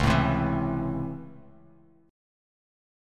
Listen to C#mM7#5 strummed